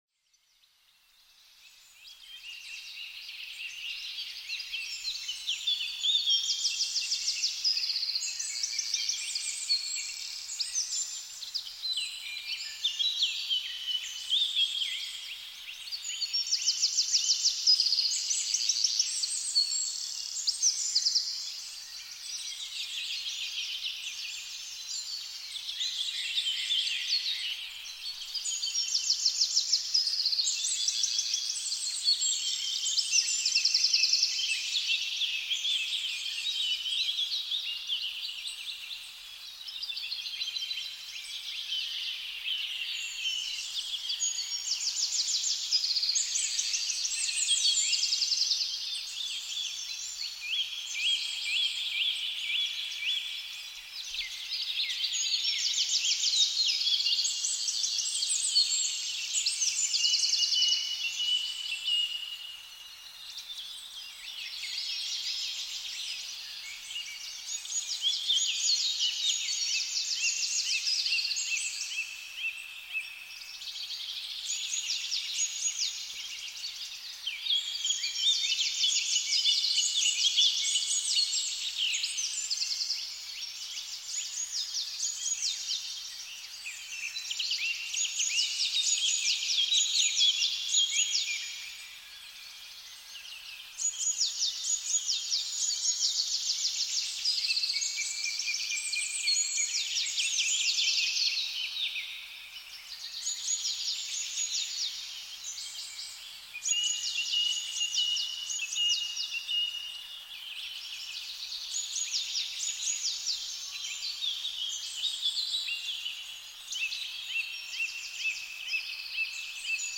Frühlingswald-Naturstimmen: Vogelstimmen im magischen Licht